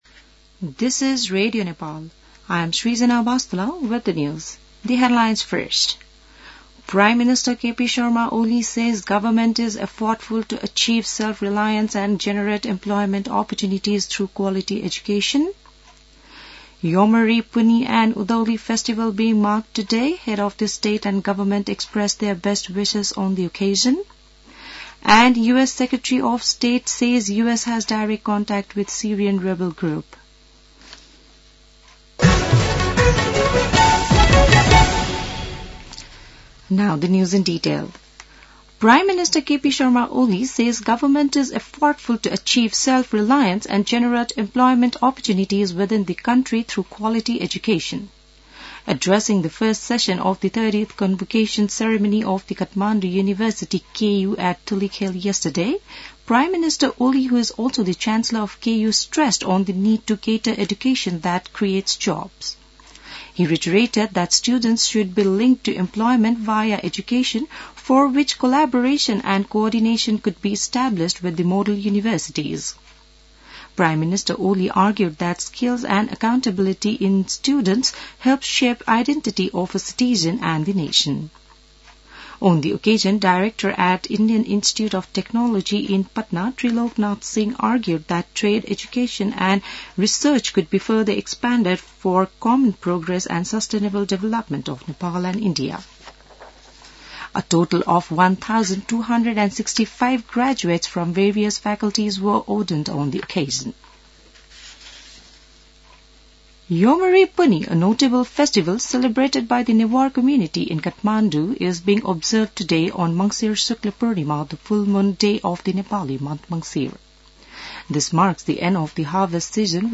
बिहान ८ बजेको अङ्ग्रेजी समाचार : १ पुष , २०८१